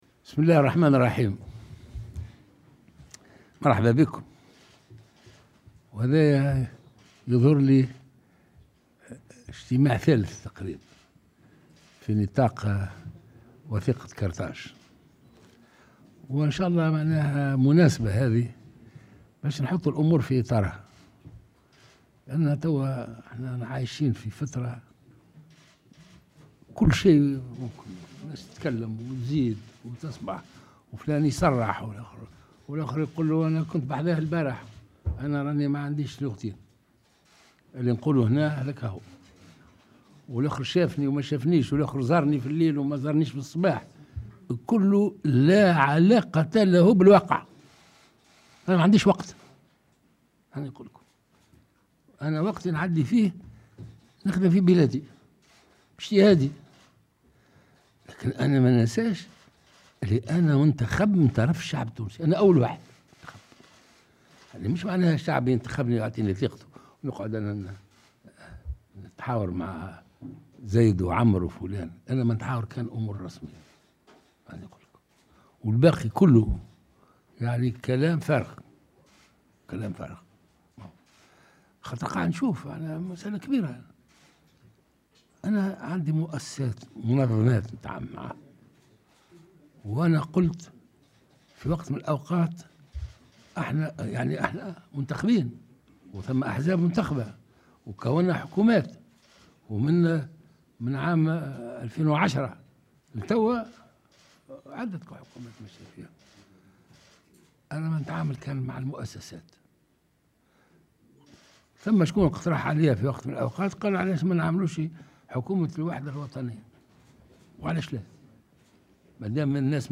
وجاء ذلك بمناسبة انطلاق اجتماع الاطراف الموقّعة على وثيقة قرطاج، والمنعقد حاليا في قصر قرطاج بحضور ممثلي 4 منظمات وطنية و5 أحزاب سياسية.